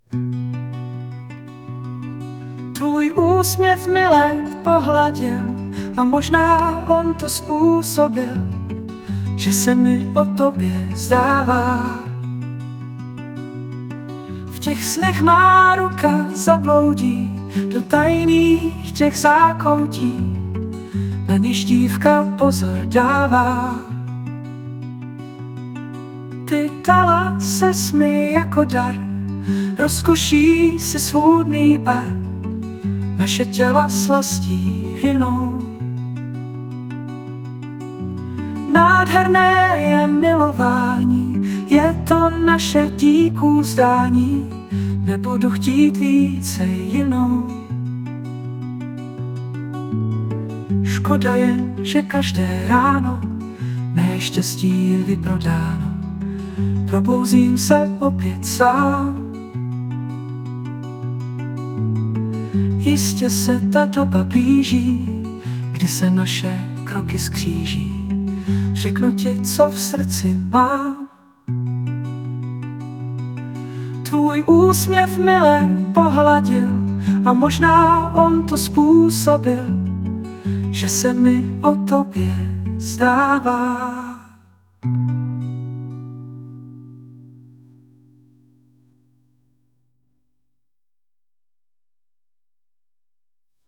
* hudba, zpěv: AI
Tvůj dojem ti neberu, hudba je skutečně jemná a trochu upozaděná.